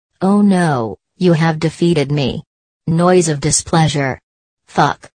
panther_death.mp3